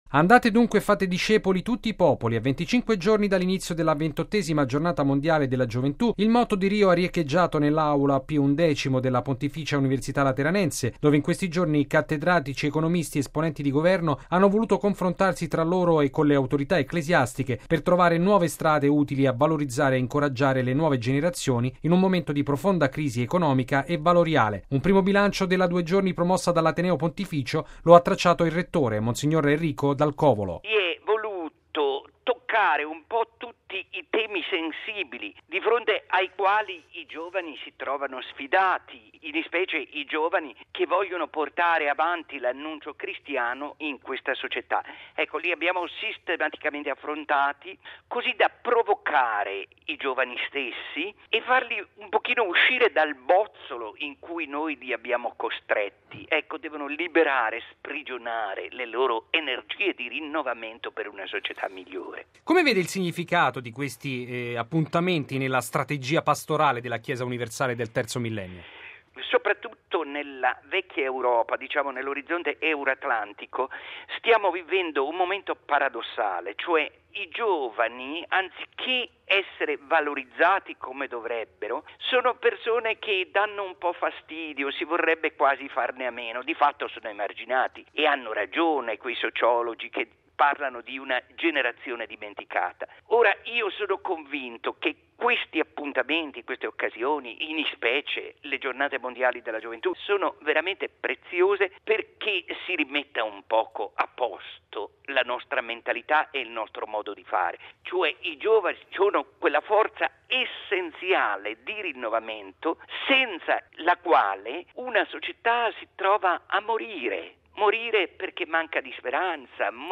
L’iniziativa ha visto confrontarsi esponenti del mondo delle istituzioni, delle imprese, della diplomazia e dell’università, in vista della prossima Giornata Mondiale della Gioventù. Il servizio